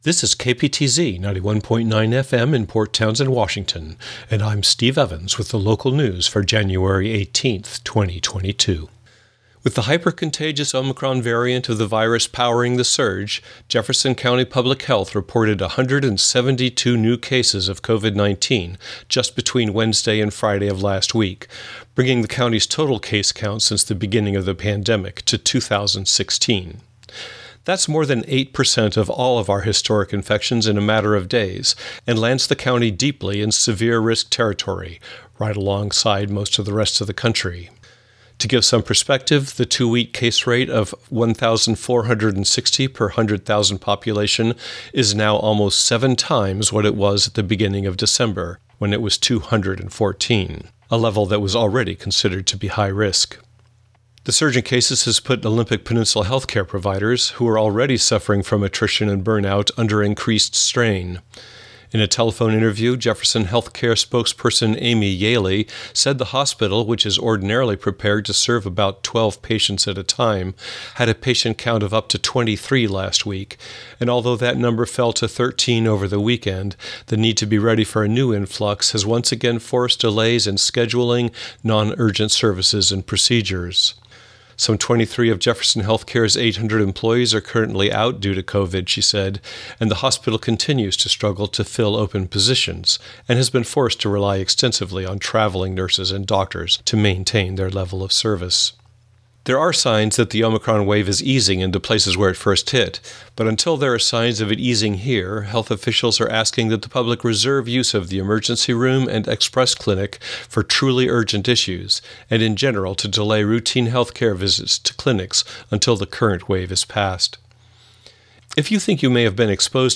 Genre: Radio News.